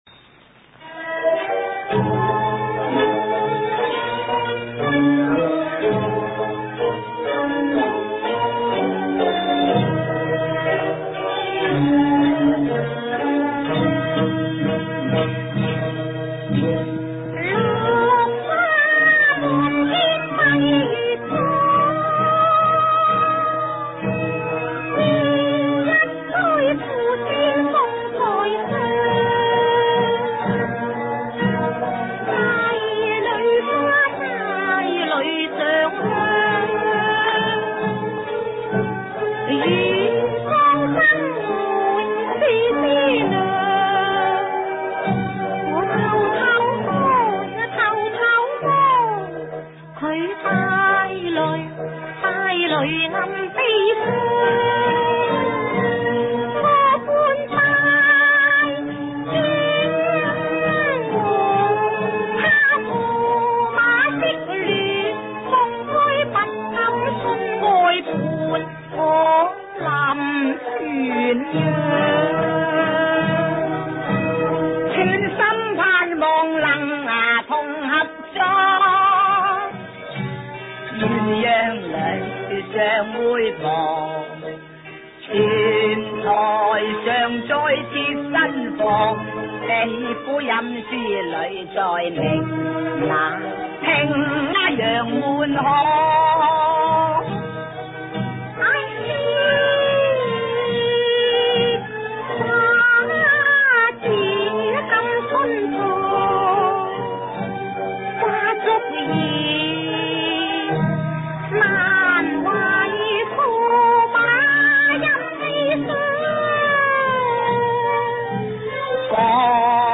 粵劇